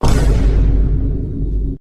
[SOUND] Vine Boom.ogg